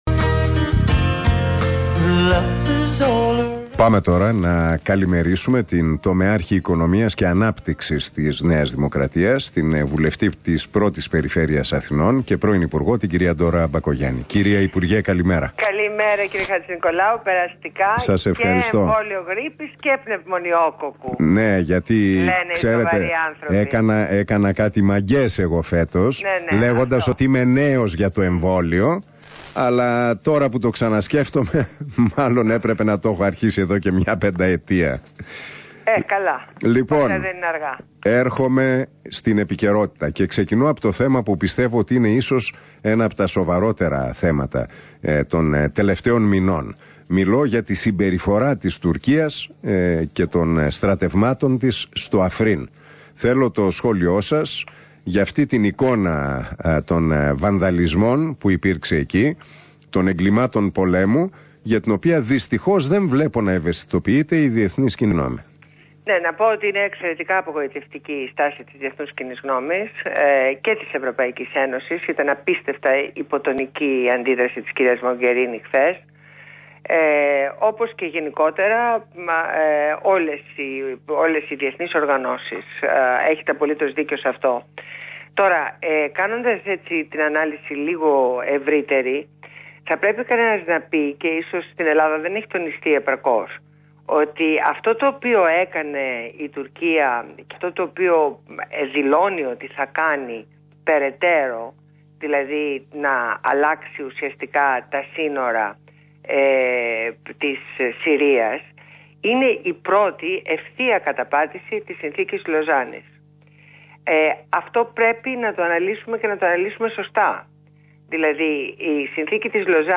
Συνέντευξη στο ραδιόφωνο News247